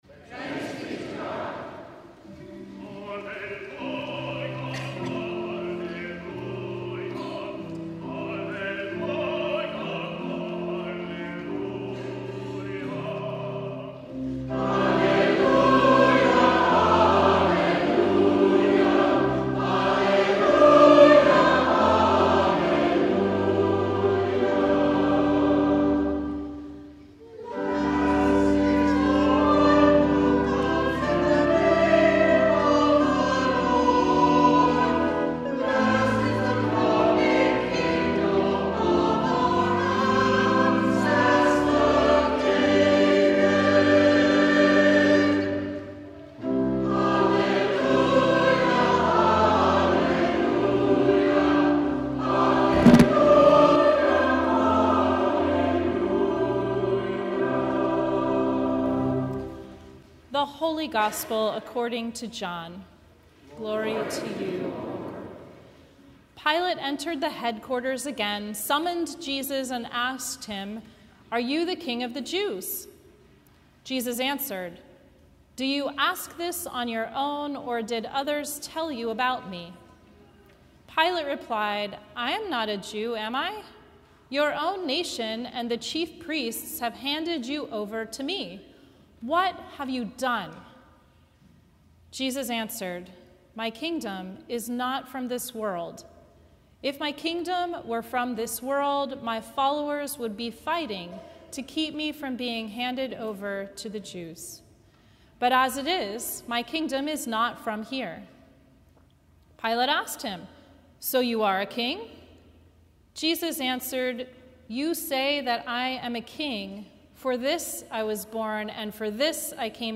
Sermon from Christ the King Sunday